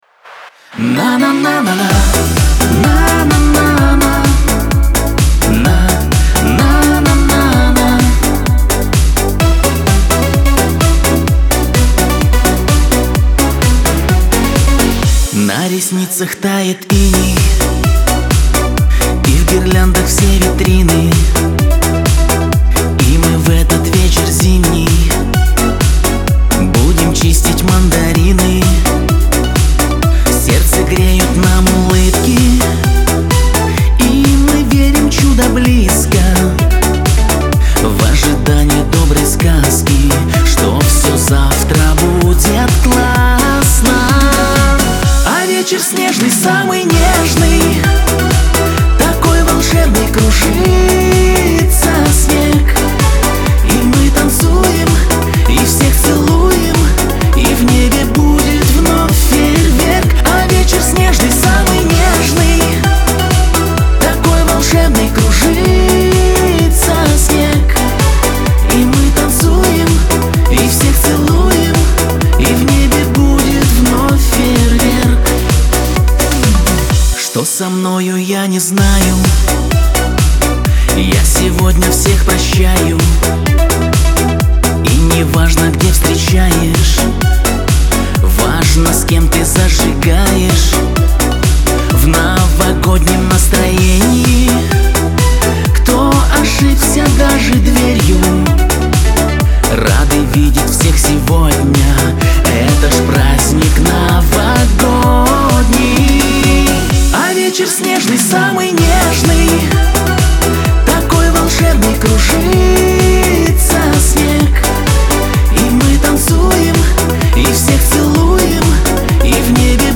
pop
диско , эстрада